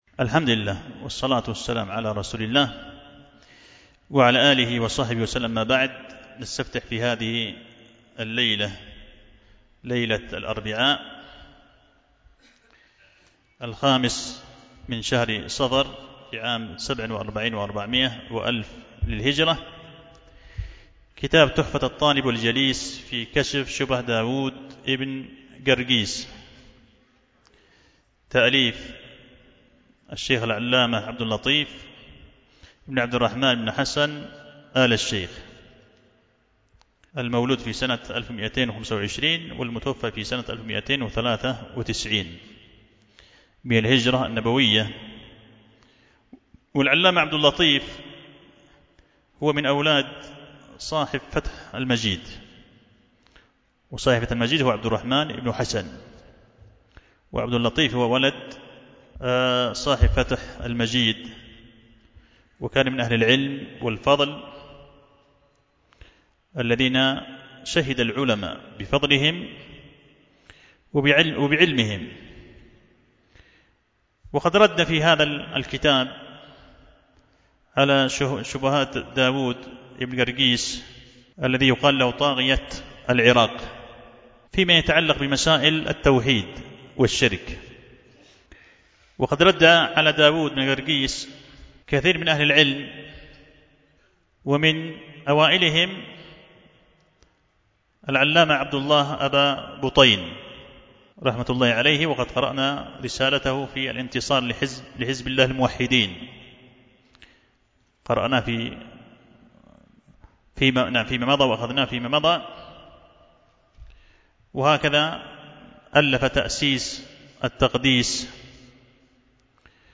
ضمن سلسلة الدروس المفيدة في فنون عديدة